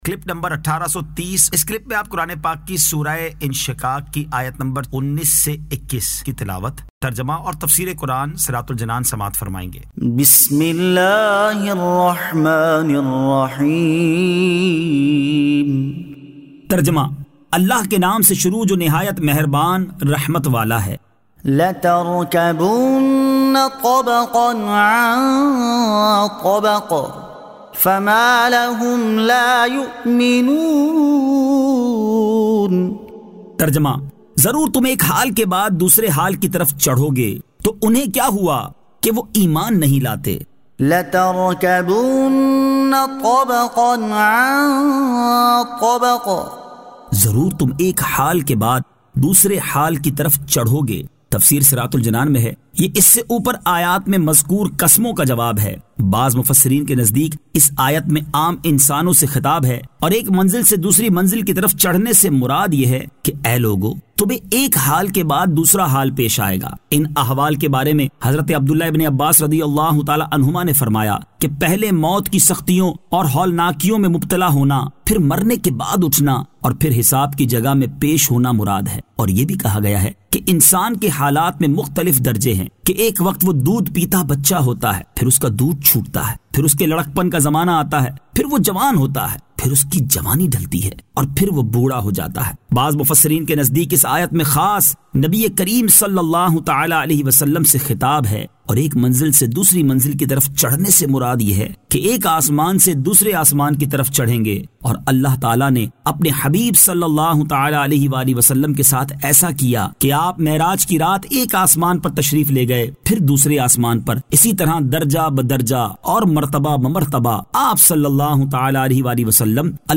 Surah Al-Inshiqaq 19 To 21 Tilawat , Tarjama , Tafseer